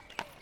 马蹄2.wav